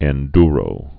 (ĕn-drō, -dyrō)